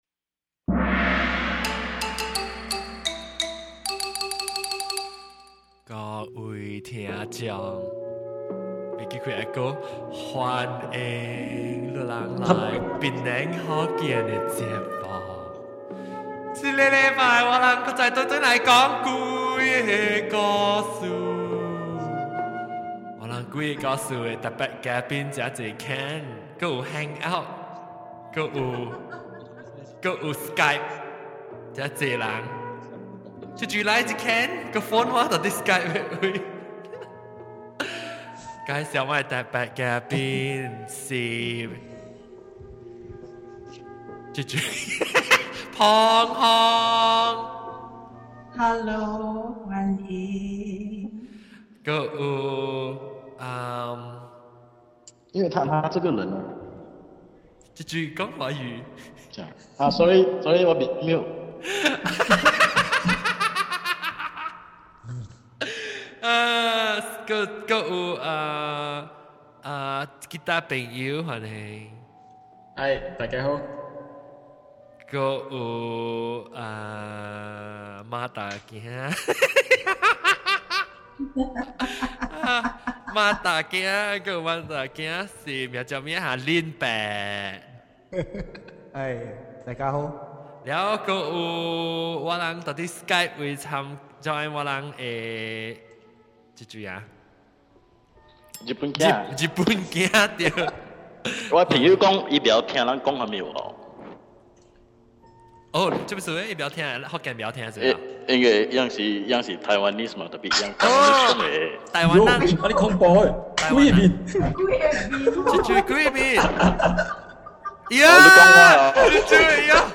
With extra pre show and post show chatter.